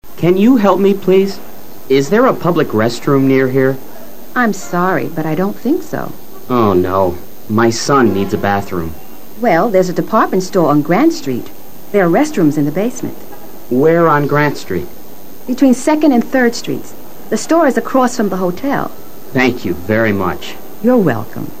Listenings y Conversaciones :: Elementary
Woman: